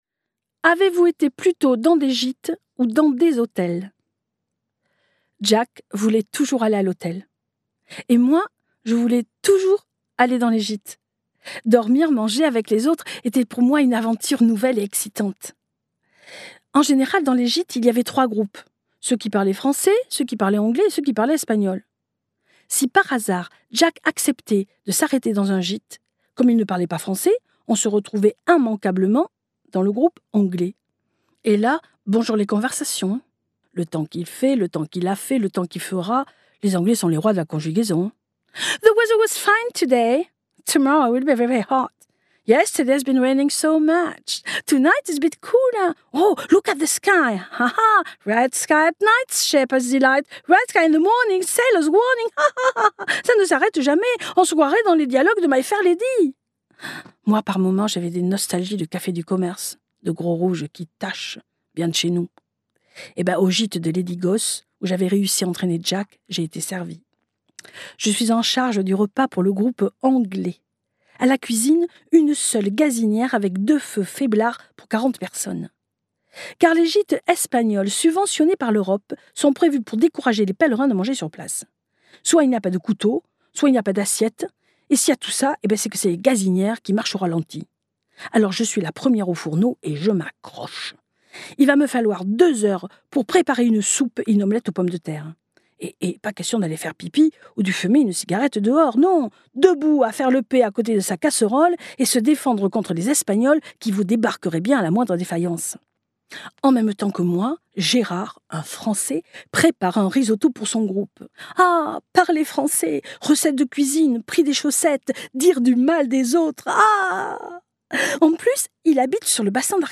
Plus de 300 représentations de ce spectacle créé au Festival Off Avignon 2005 Durée : 01H05 × Guide des formats Les livres numériques peuvent être téléchargés depuis l'ebookstore Numilog ou directement depuis une tablette ou smartphone.